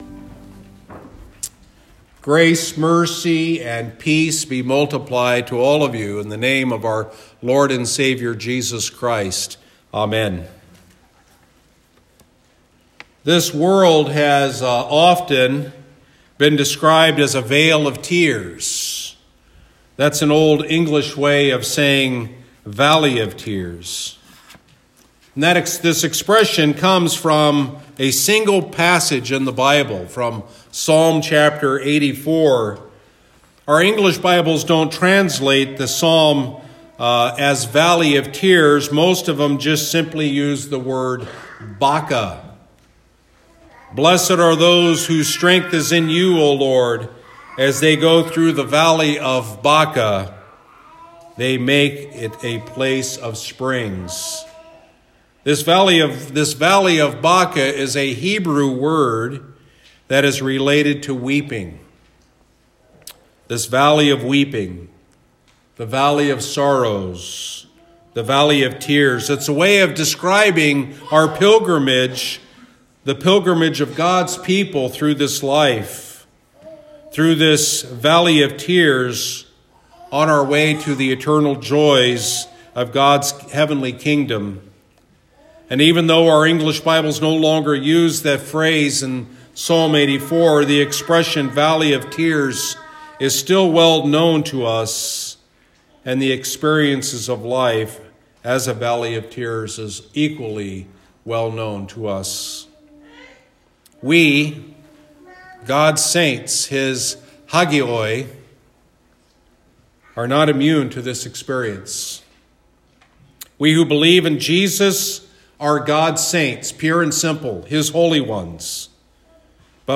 Sunday Sermon — “No More Tears”